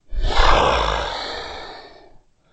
龙的声音 " 龙的咆哮 温和12
描述：为制作史瑞克而制作的龙声。使用Audacity录制并扭曲了扮演龙的女演员的声音。
Tag: 生物 发声 怪物